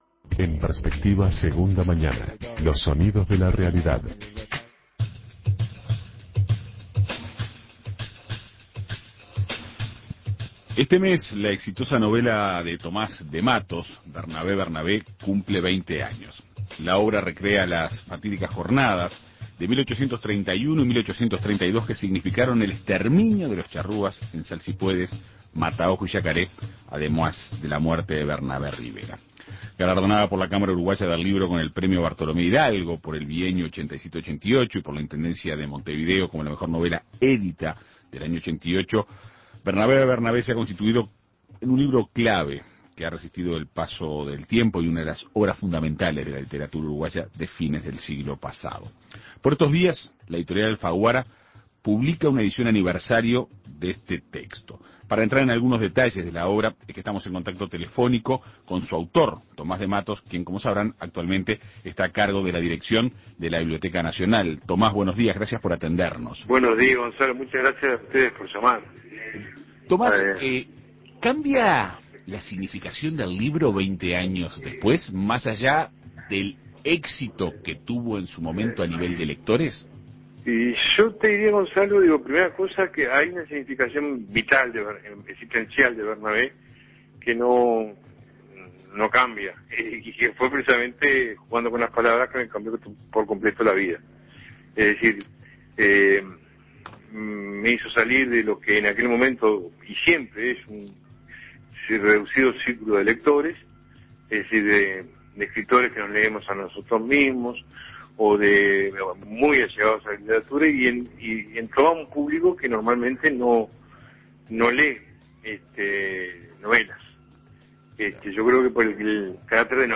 En Perspectiva Segunda Mañana dialogó con su autor.